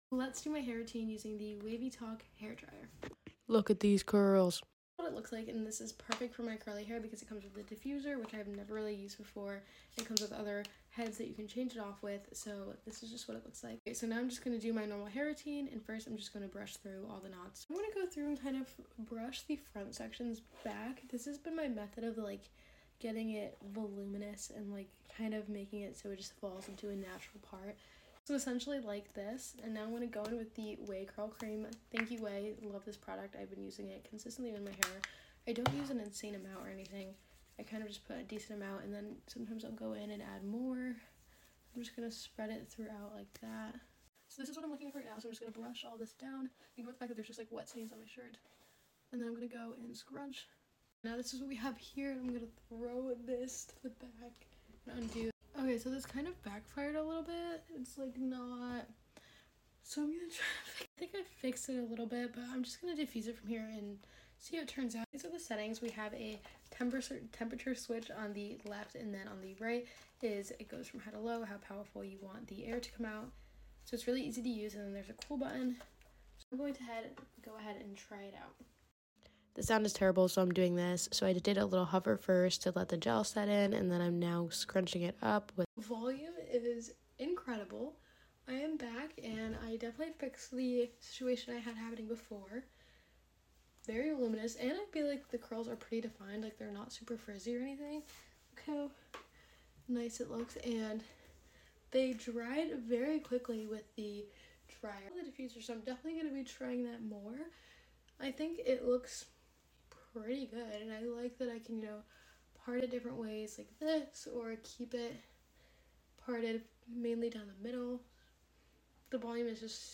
Love the wavytalk diffuser/blow dryer sound effects free download